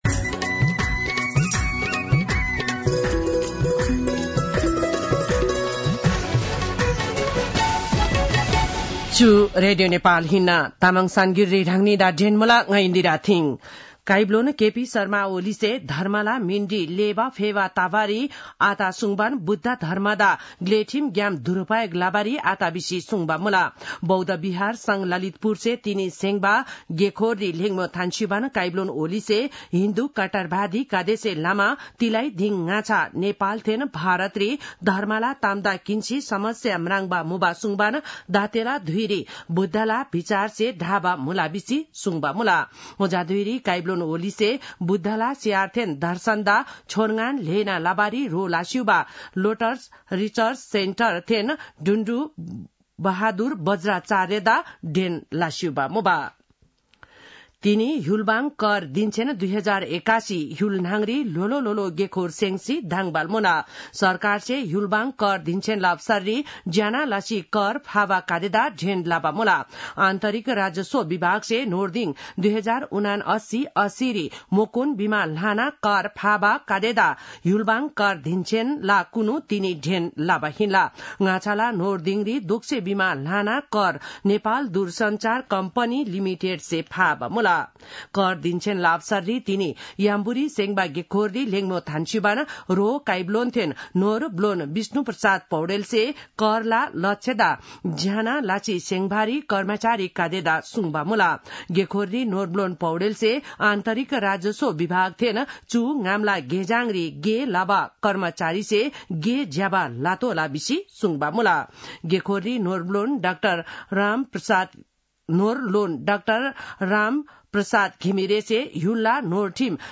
तामाङ भाषाको समाचार : २ मंसिर , २०८१
Tamang-News-8-1.mp3